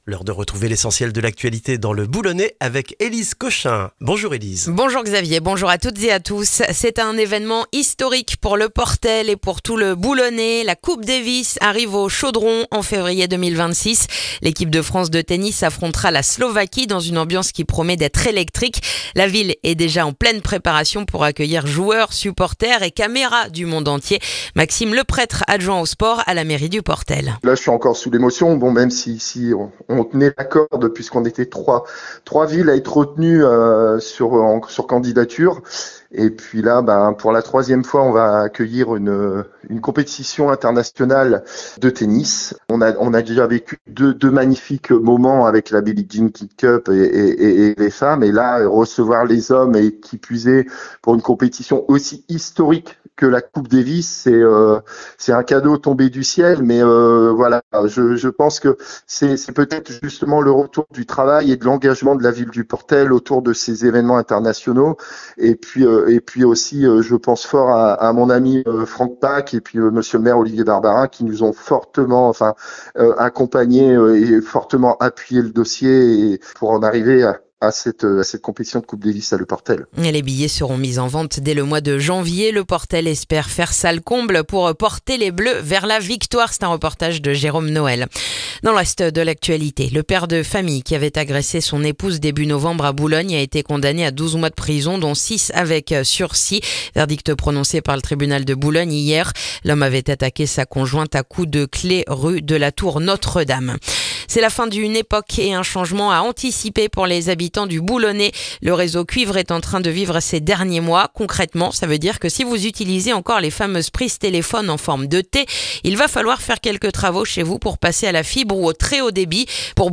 Le journal du jeudi 4 décembre dans le boulonnais